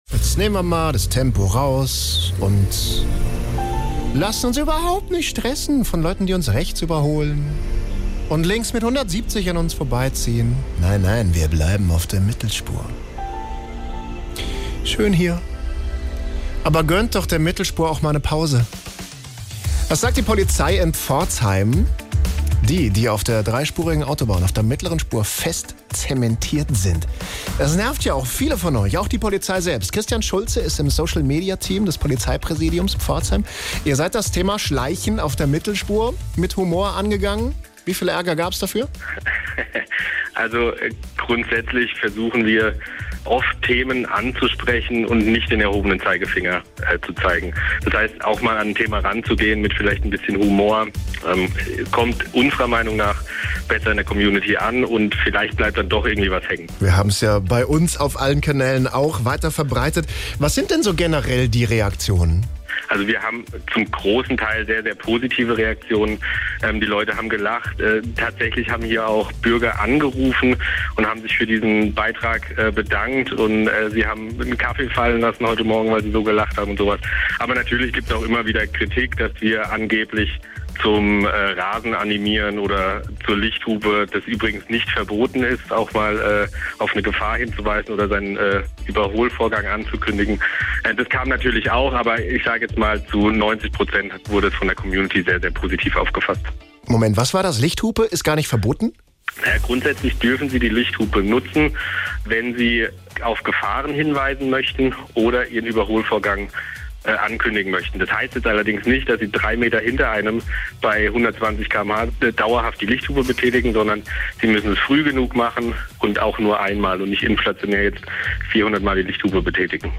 Im Interview hat der Polizeisprecher außerdem verraten, wann wir die Lichthupe verwenden dürfen und wann es eindeutig zu viel des Guten ist: